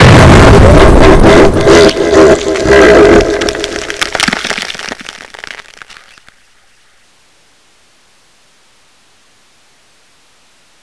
fall2.wav